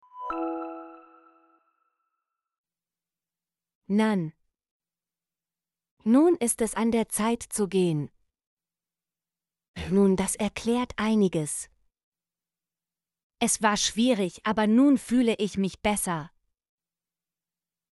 nun - Example Sentences & Pronunciation, German Frequency List